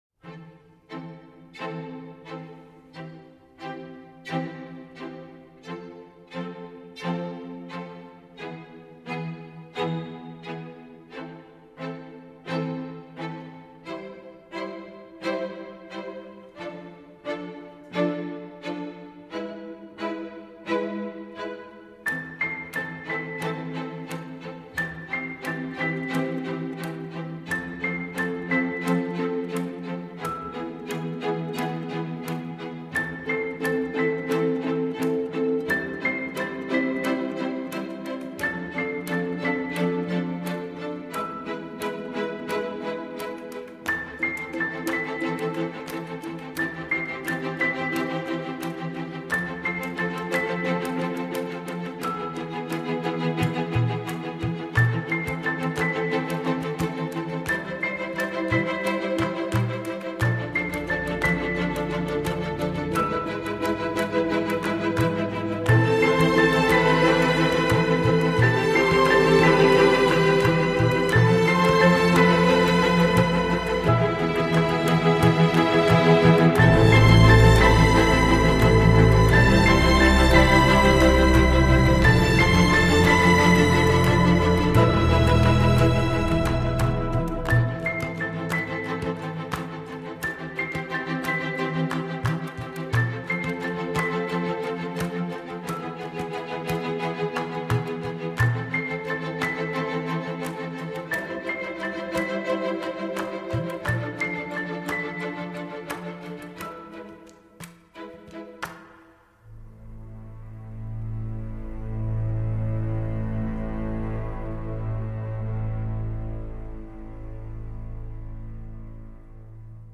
avec une certaine retenue